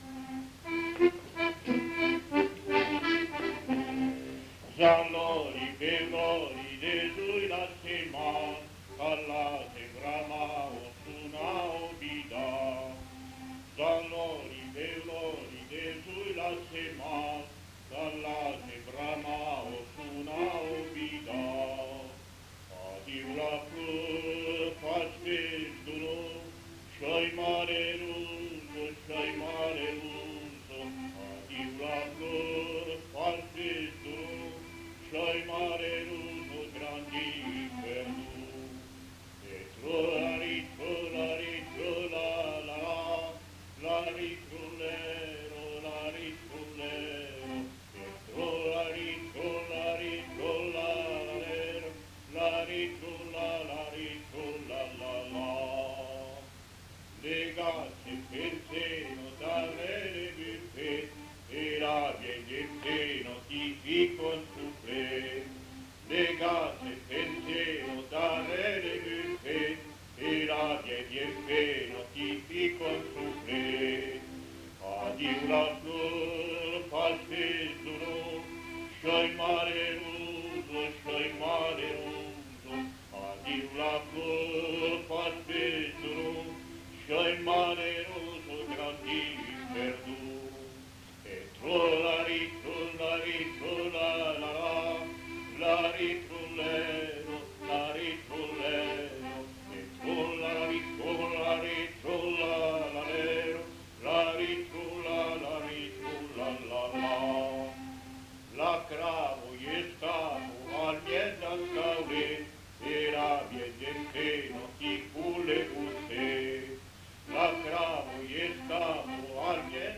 Lieu : Villardonnel
Genre : chanson-musique
Type de voix : voix d'homme
Production du son : chanté ; fredonné
Instrument de musique : accordéon diatonique
Danse : java-valse